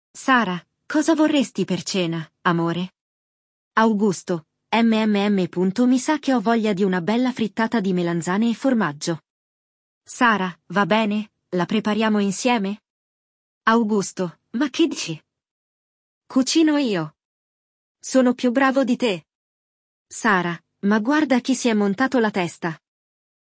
O casal deste diálogo vai conversar justamente sobre isso!
Il dialogo